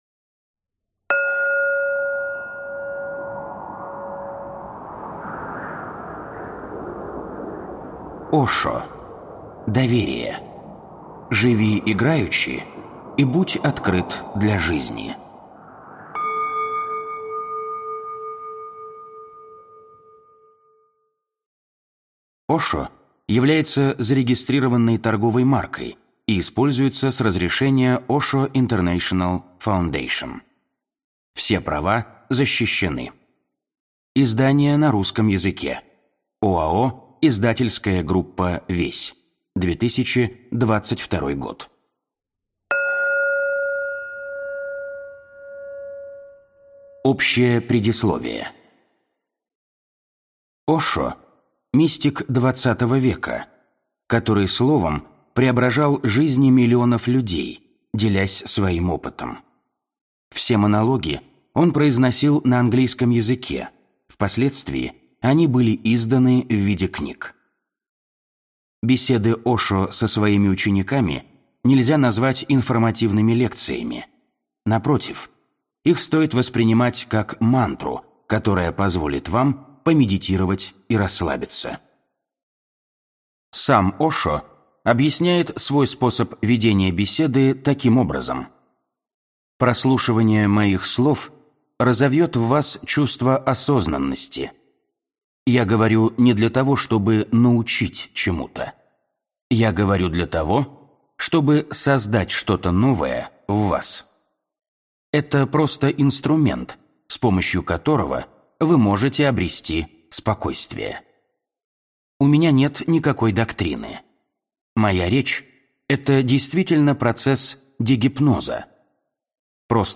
Аудиокнига Доверие. Живи играючи и будь открыт для жизни | Библиотека аудиокниг